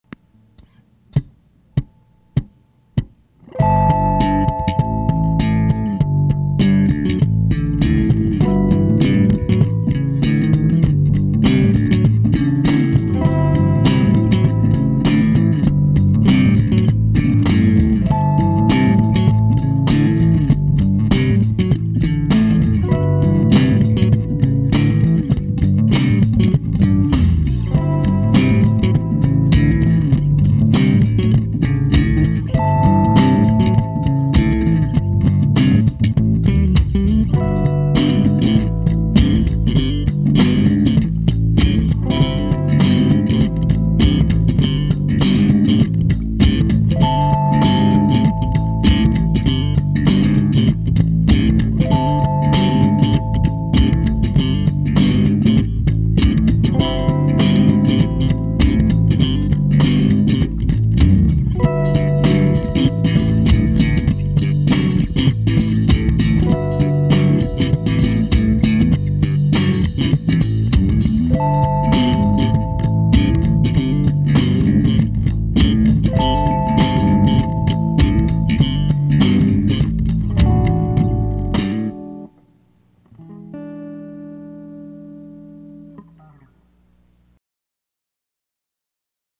This song has three bass tracks :D  (circa 1995)
I'm playing guitar, bass, bass, bass, and drums, into the Tascam 8-track: